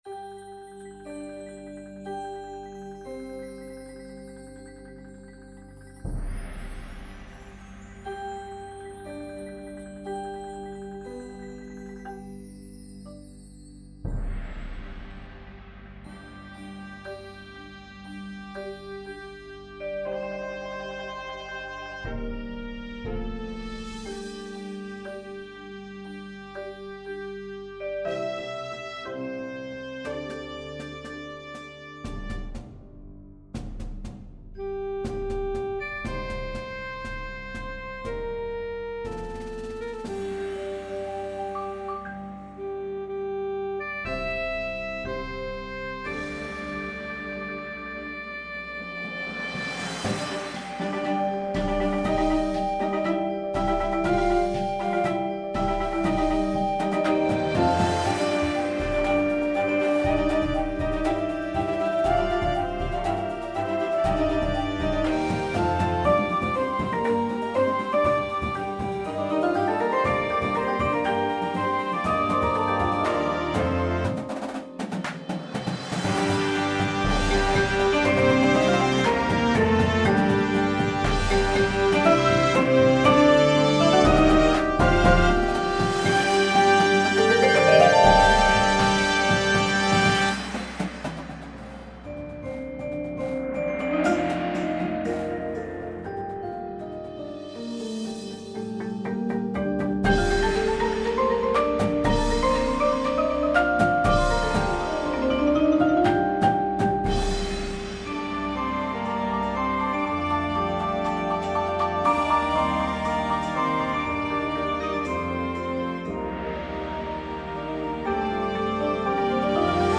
• Flute
• Clarinet 1, 2
• Alto Sax
• Trumpet 1, 2
• Horn F
• Low Brass 1, 2
• Tuba
• Snare Drum
• Tenors
• Bass Drums
• Front Ensemble